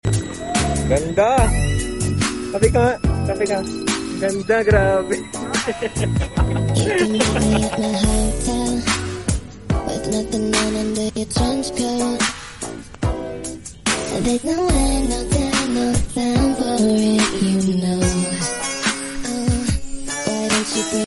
F Fresh Helmet Cleaner Vendo Machine! sound effects free download